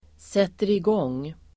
Uttal: [seterig'ång:]